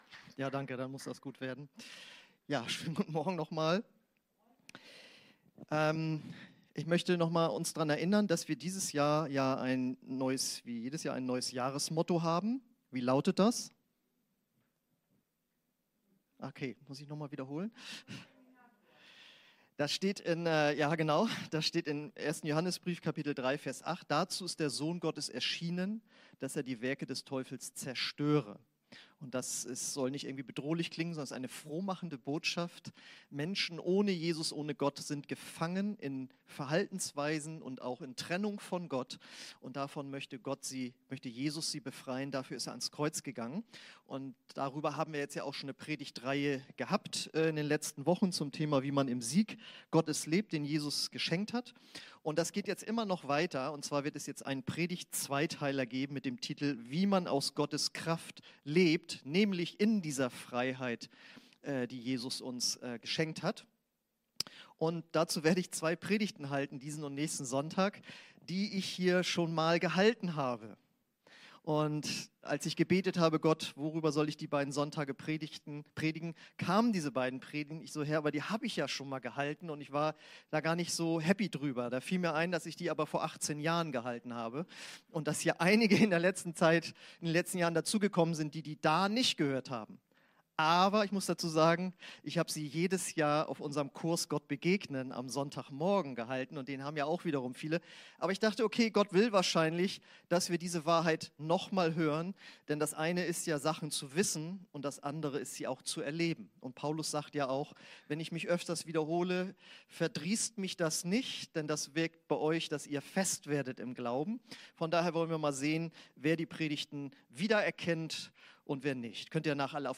Predigten – OASIS Kirche